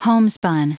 Prononciation du mot homespun en anglais (fichier audio)
Prononciation du mot : homespun